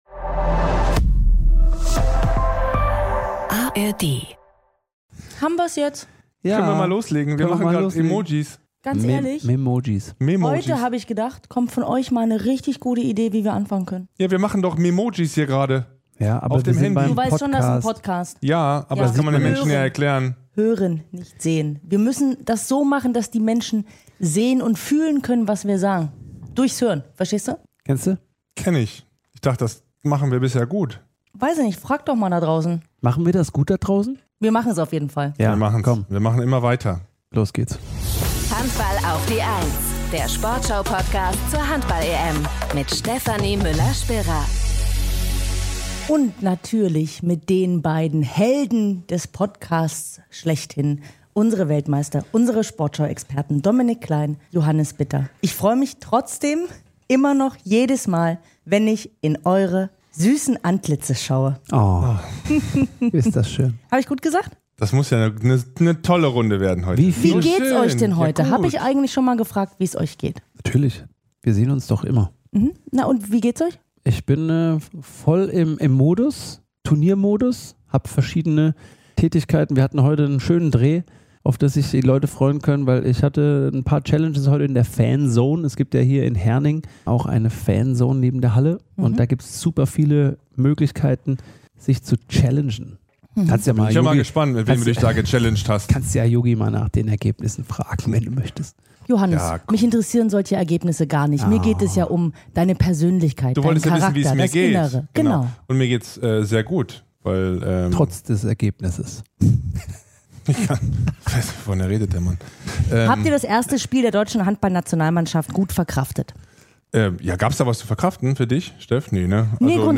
Rückraumspieler